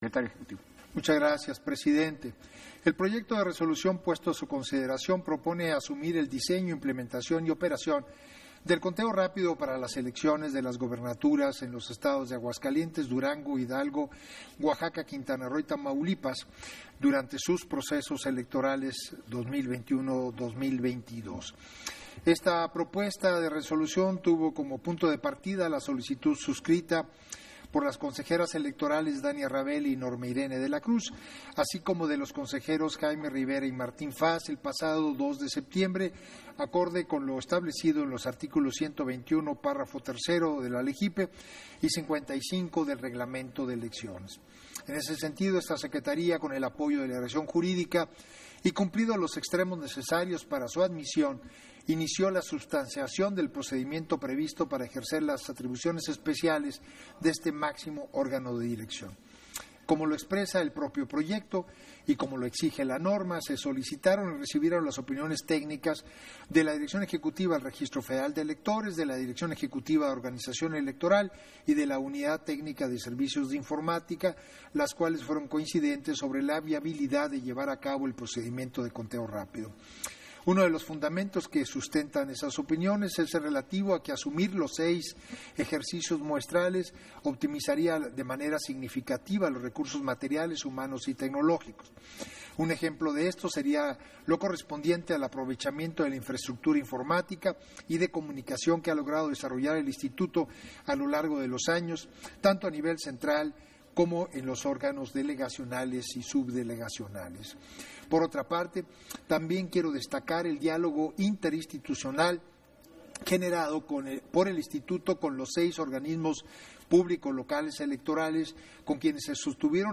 Intervención de Edmundo Jacobo Molina, En sesión Extraordinaria, en el punto en que se aprueba ejercer la facultad de asunción parcial para incrementar el conteo rápido en las Elecciones 2022